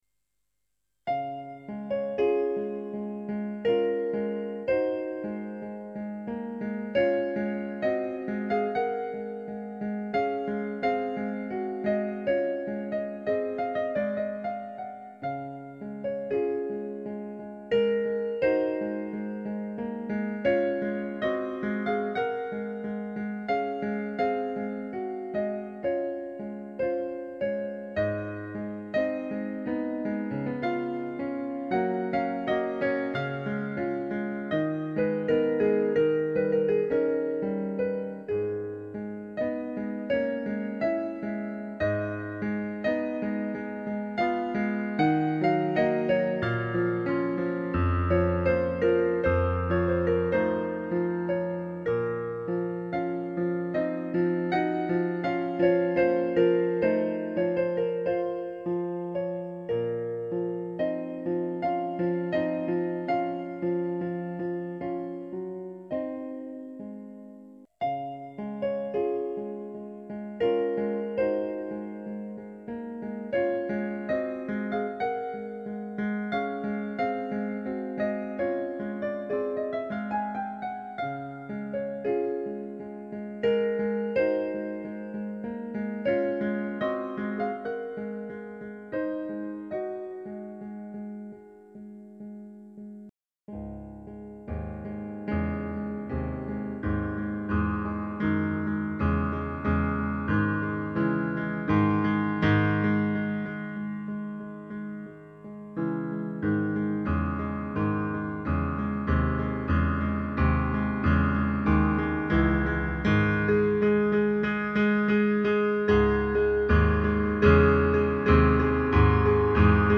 Il Pianoforte